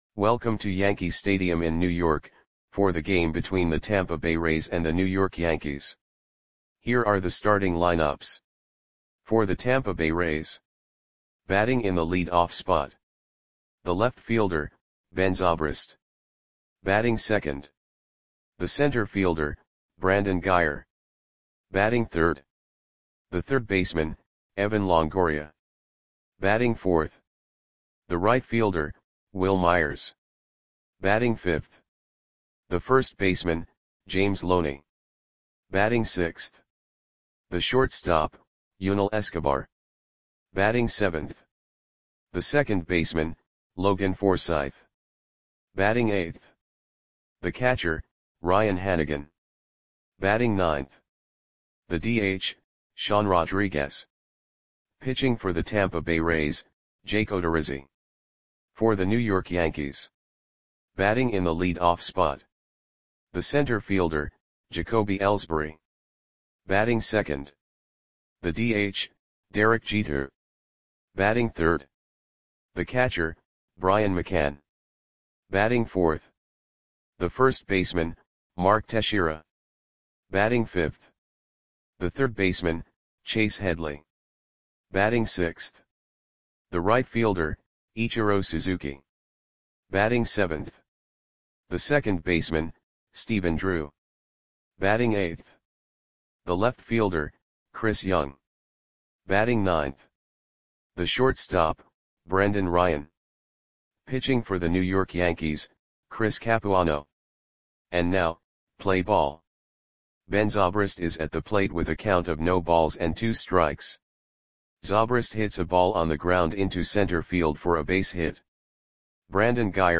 Audio Play-by-Play for New York Yankees on September 10, 2014
Click the button below to listen to the audio play-by-play.